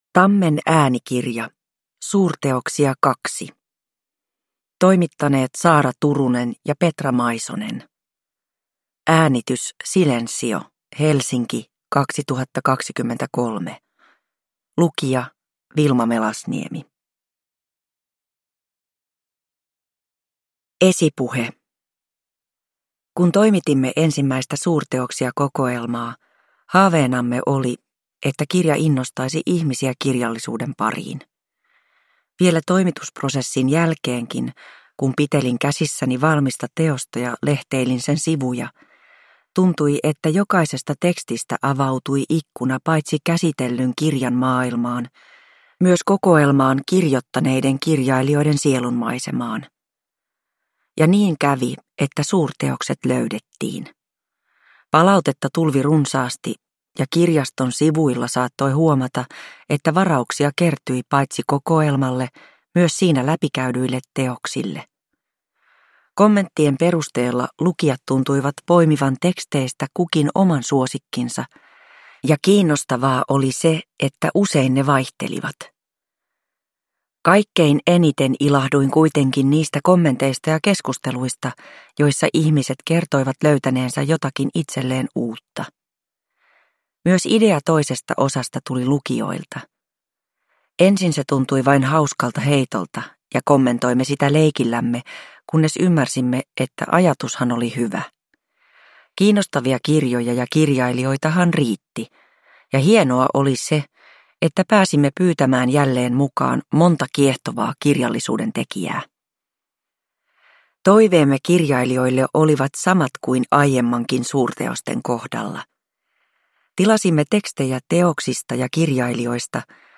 Suurteoksia II – Ljudbok – Laddas ner
Uppläsare